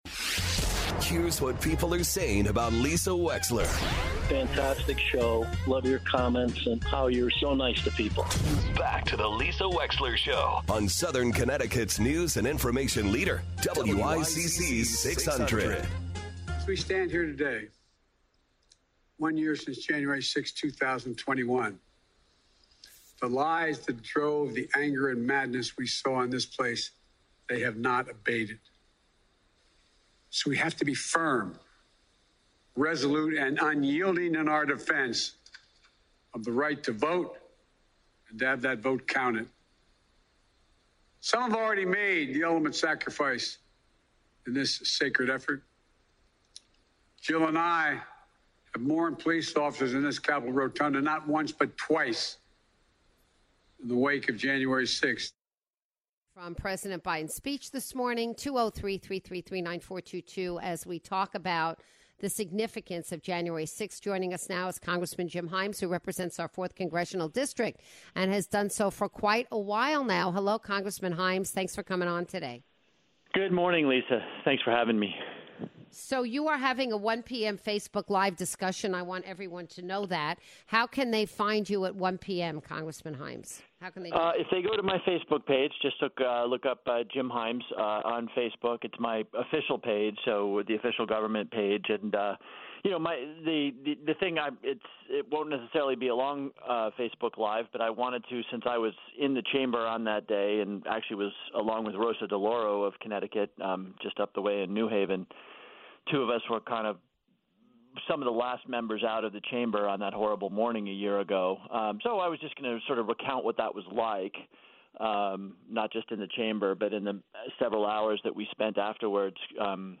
US Representative Jim Himes joins the show to remember the events in Washington D.C. on January 6th 2021.